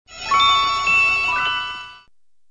New Mail (17K)
grinch_new_mail_.wav